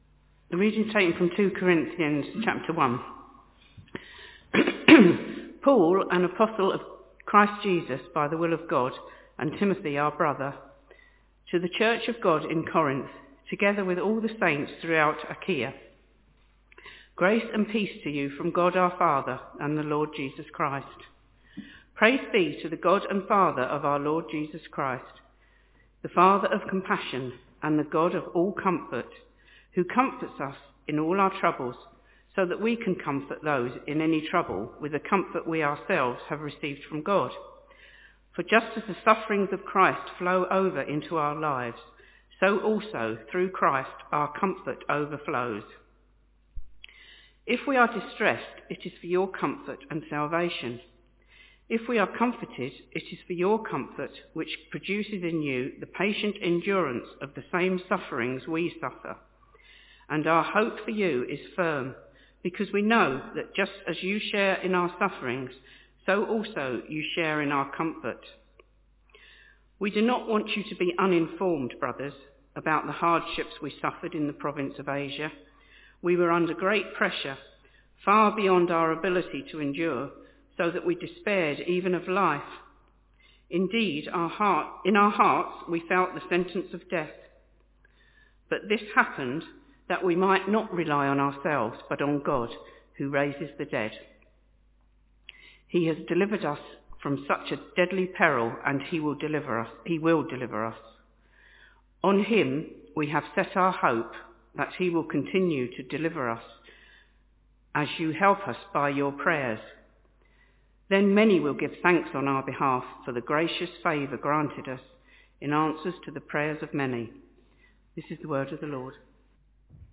Talk starts after reading at 2.05